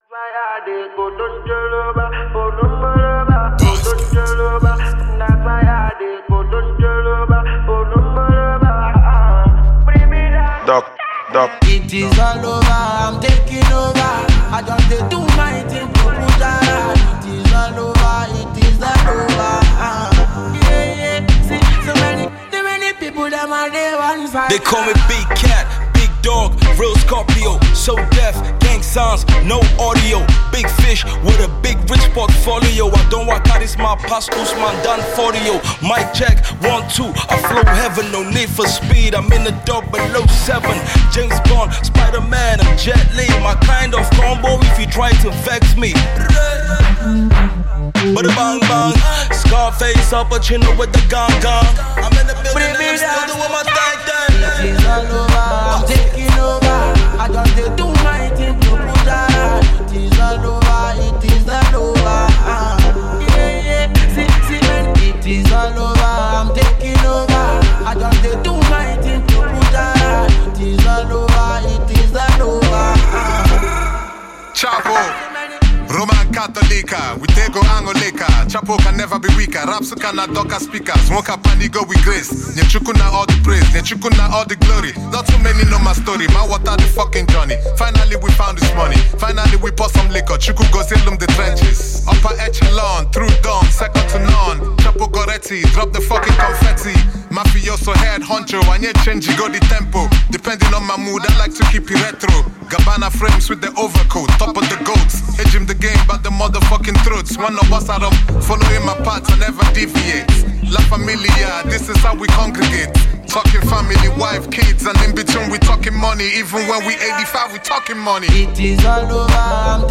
African Music Genre: Afrobeats Released